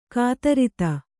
♪ kātaritana